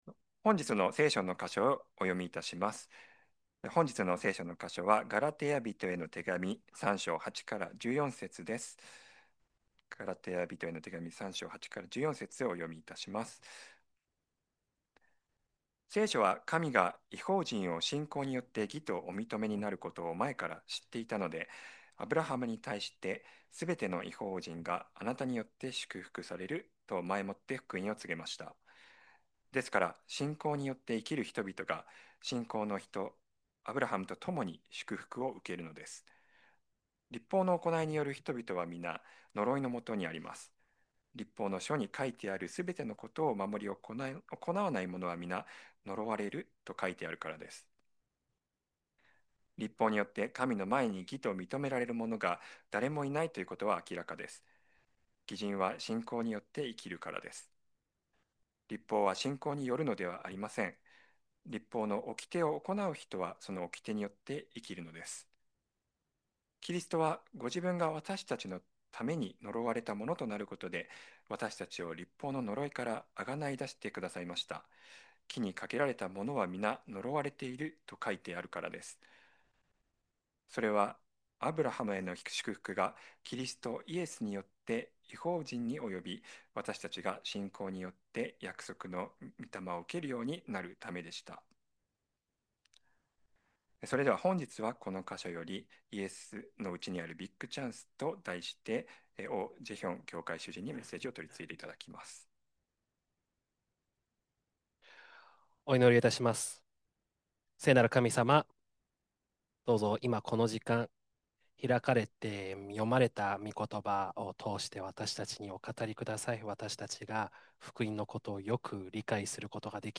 2026年2月8日礼拝 説教 「イエスのうちにあるビッグチャンス」 – 海浜幕張めぐみ教会 – Kaihin Makuhari Grace Church